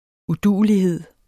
Udtale [ uˈduˀəliˌheðˀ ]